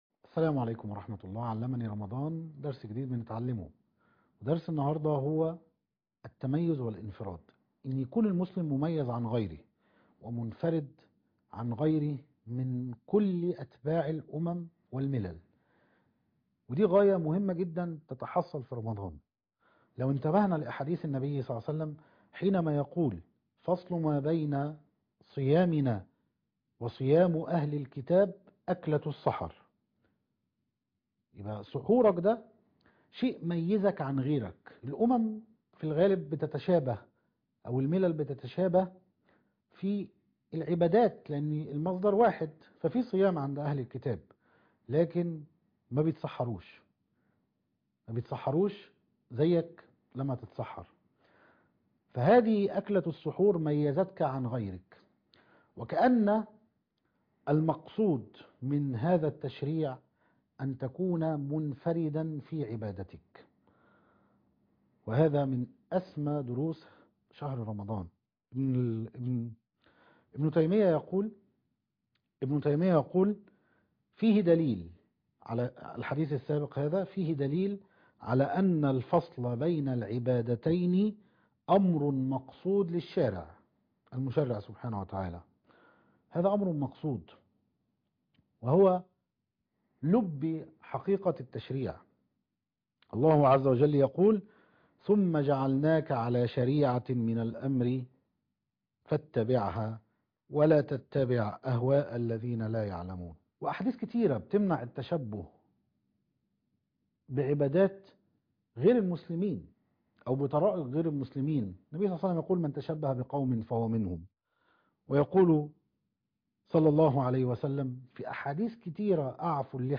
المقرأة - سورة يوسف ص 243